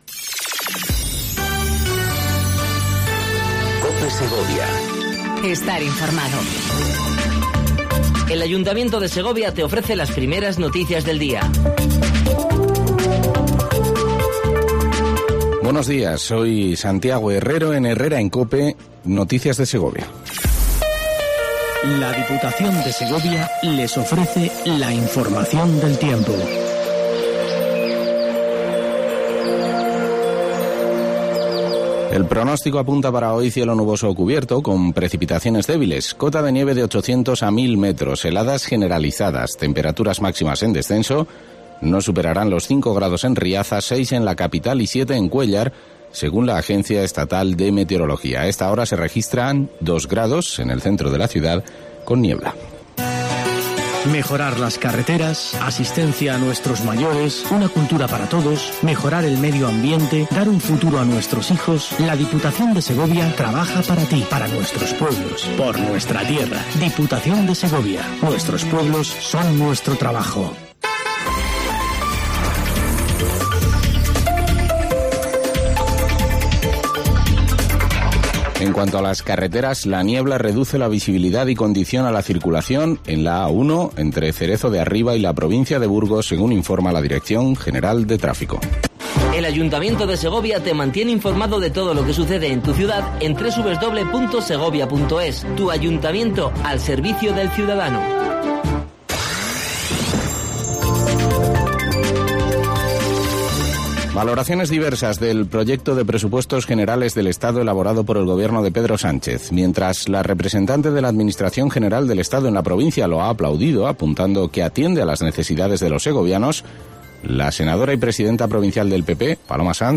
INFORMATIVO 07:55 COPE SEGOVIA 17/01/19
AUDIO: Primer informativo local en cope segovia